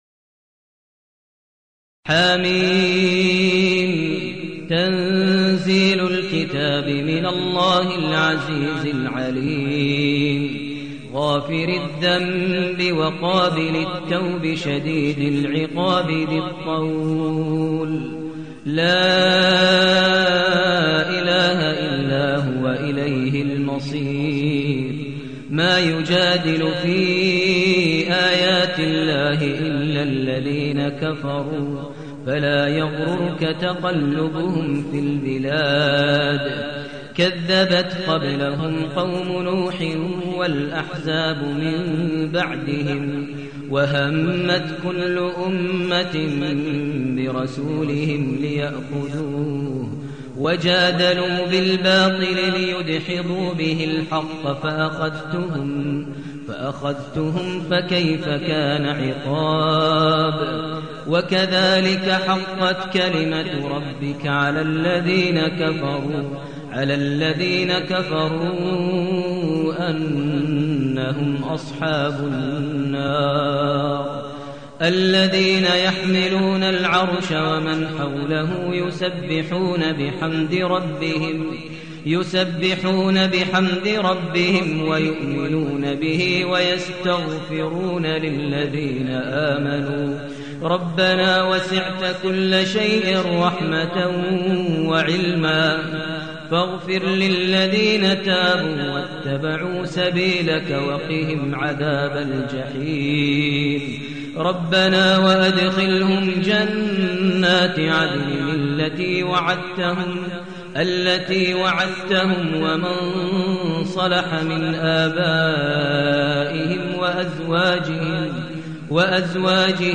المكان: المسجد النبوي الشيخ: فضيلة الشيخ ماهر المعيقلي فضيلة الشيخ ماهر المعيقلي غافر